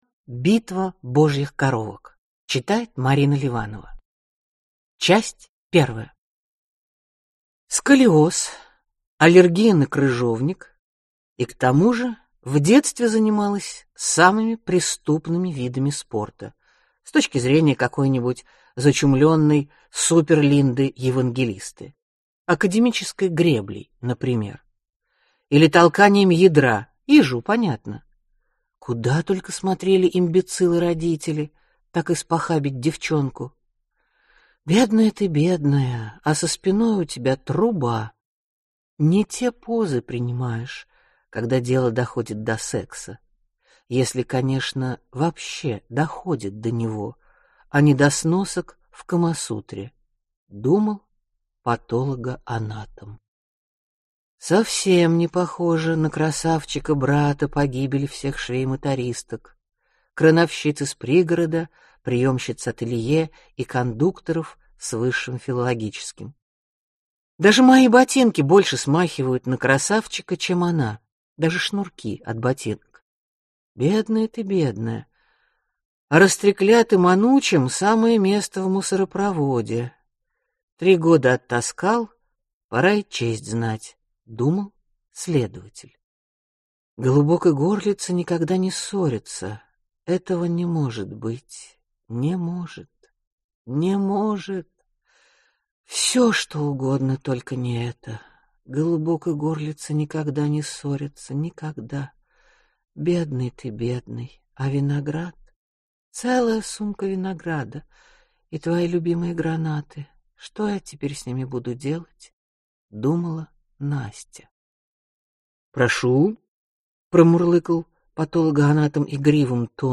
Аудиокнига Битвы божьих коровок | Библиотека аудиокниг